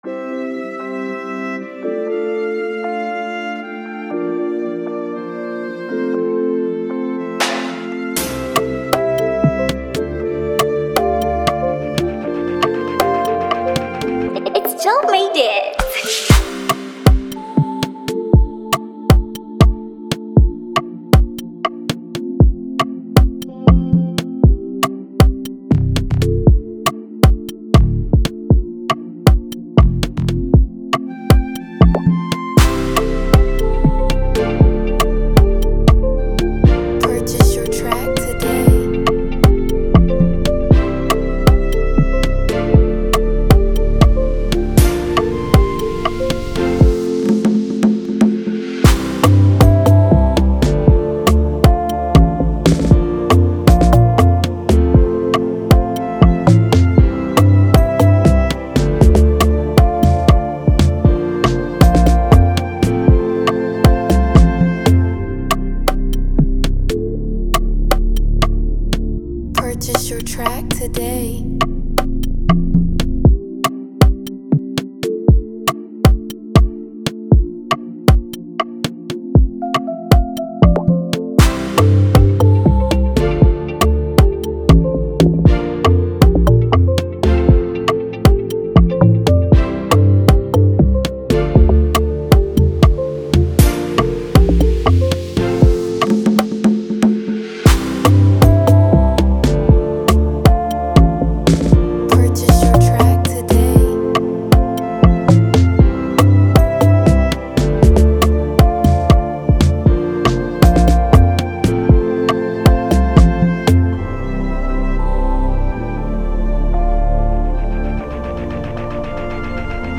BPM : 118BPM Key : Dm Buy 2 Get 1 Free
Category: AFROBEAT